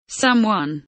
someone kelimesinin anlamı, resimli anlatımı ve sesli okunuşu